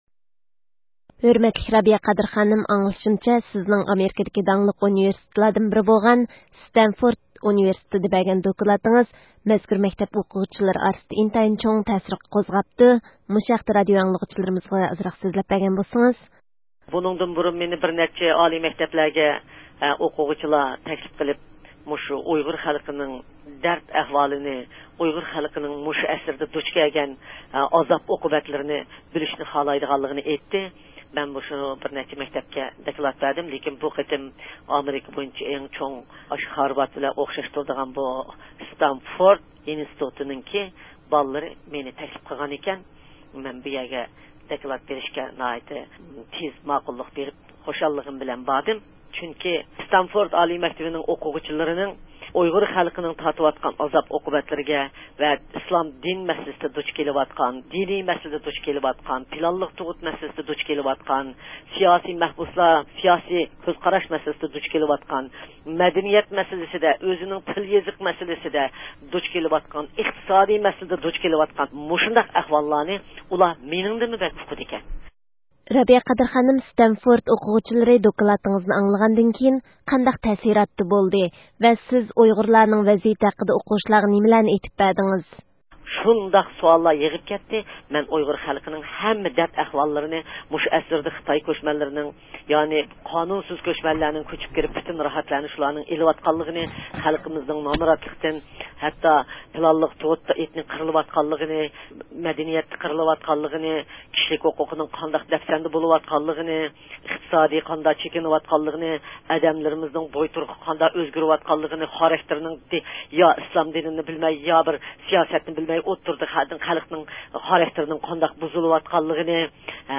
رابىيە قادىر خانىم سانفىرانسىسكو شەھىرىدىن قايتىپ كېلىپ، بۈگۈن ئىستانسىمىزنىڭ زىيارىتىنى قوبۇل قىلىپ، ستانفورد ئۇنىۋېرسىتېتىدا بەرگەن دوكلاتىنىڭ ئىنتايىن نەتىجىلىك بولغانلىقىنى، بولۇپمۇ پات ئارىدا ئامېرىكىنىڭ ھەر قايسى ساھەلىرىگە ئورۇنلىشىدىغان ياش زىيالىلار ئارىسىدا ، ئۇيغۇر مەسىلىسىگە قارىتا بەلگىلىك كۆز قاراشنىڭ تىكلەنگەنلىكىدىن تولىمۇ سۆيۈنگەنلىكىنى بىلدۈرۈش بىلەن بىرگە بۇنىڭدىن كېيىن ئۇنۋېرسىتېت ۋە تەتقىقات مەركەزلىرىدە بۇنىڭغا ئوخشاش پائالىيەتلەرنى داۋاملىق ئېلىپ بارىدىغانلىئىقنى تەكىتلىدى.